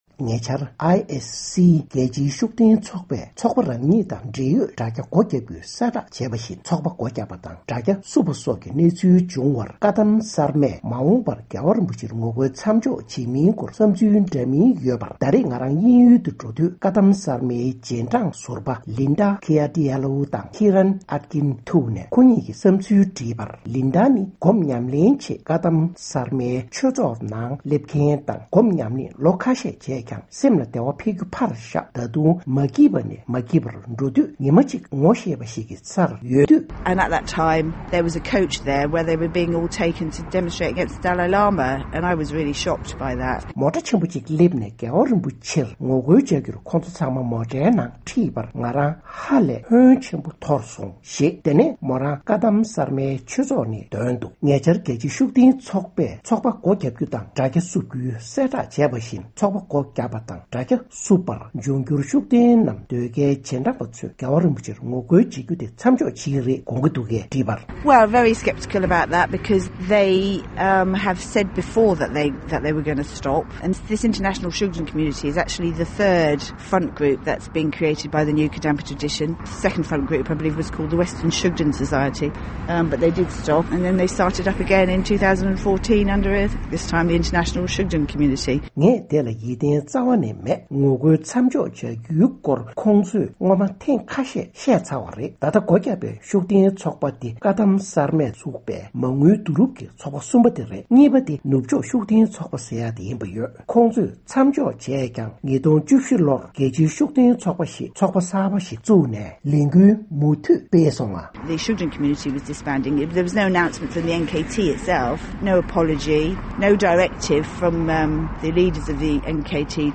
༄༅། །དབྱིན་ཡུལ་གྱི་བཀའ་གདམས་གསར་མའི་སློབ་མ་ཟུར་བ་གཉིས་ལ་འབྱུང་འགྱུར་བཀའ་གདམས་གསར་མའི་རྗེས་འབྲང་པ་ཚོས་༧གོང་ས་མཆོག་ལ་ངོ་རྒོལ་བྱེད་མིན་སྐོར་སོགས་ལ་བཅར་འདྲི་གནང་བ་ཞིག་གསན་རོགས་གནང་།